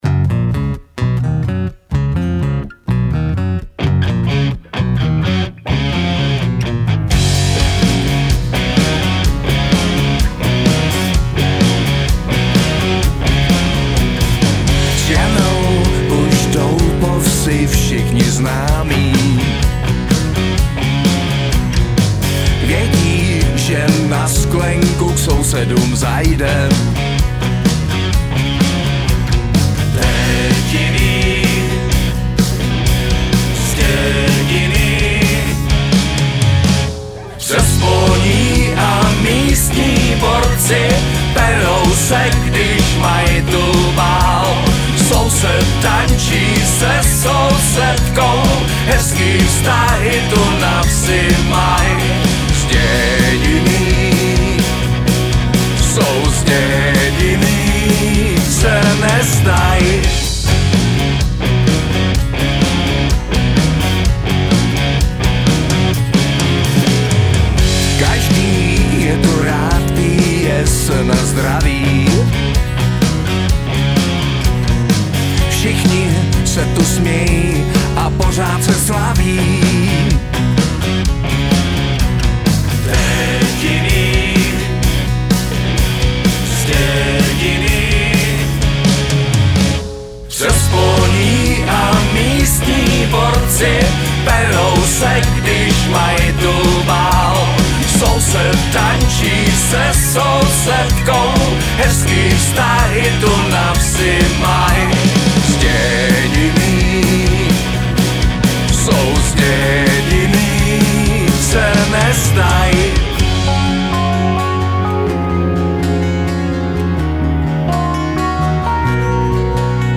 zpěv, řev, sípot
bicí
kytara, zpěv
klávesy, zpěv